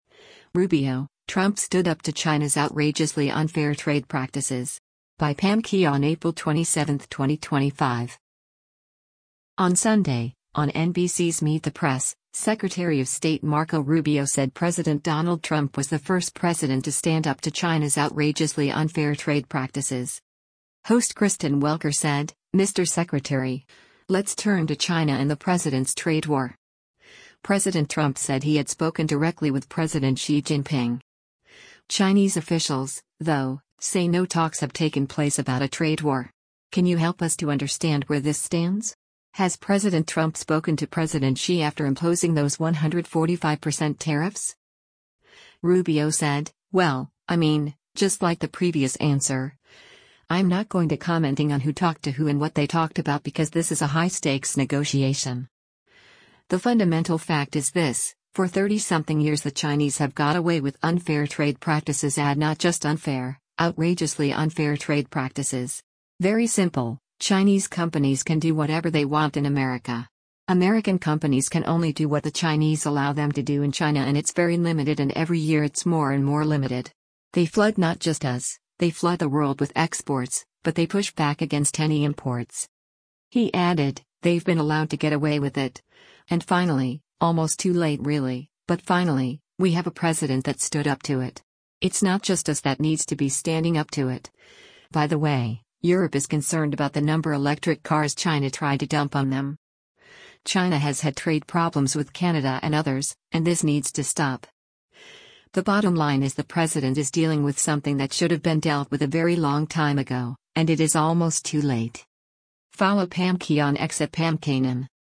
On Sunday, on NBC’s “Meet the Press,” Secretary of State Marco Rubio said President Donald Trump was the first president to stand up to China’s “outrageously unfair trade practices.”